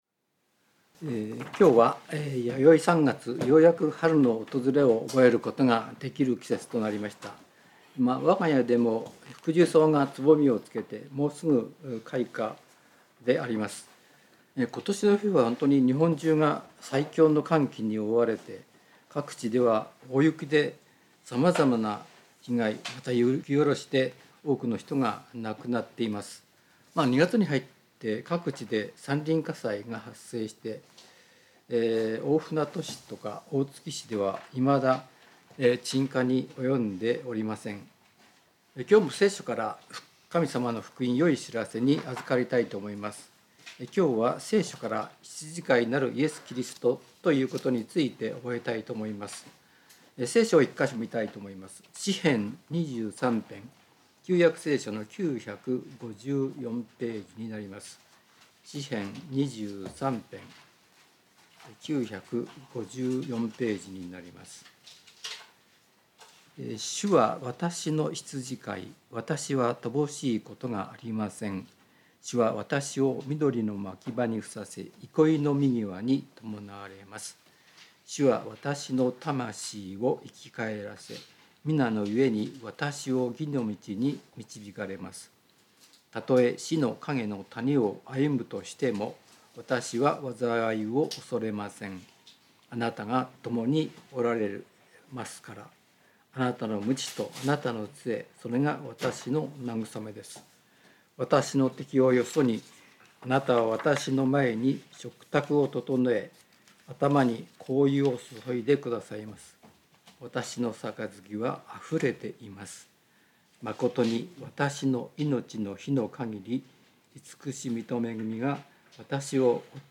聖書メッセージ No.257